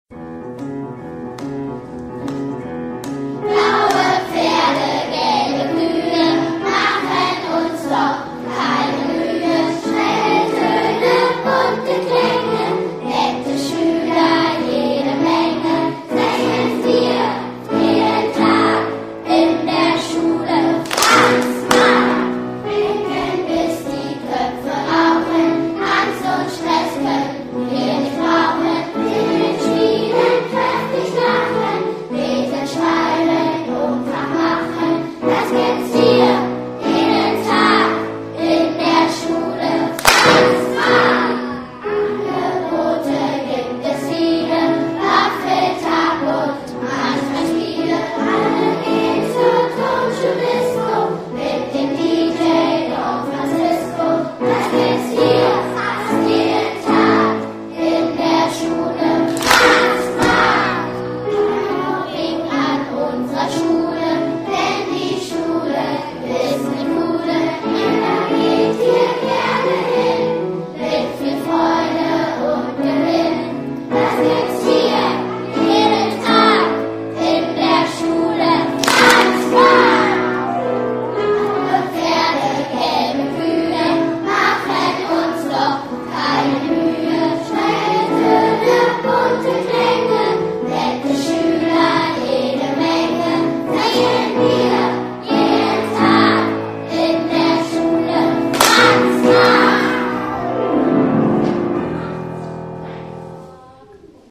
Der Chor der Franz-Marc-Grundschule
fmg_franz-marc-blues_chor.ogg